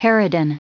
Prononciation du mot harridan en anglais (fichier audio)
Prononciation du mot : harridan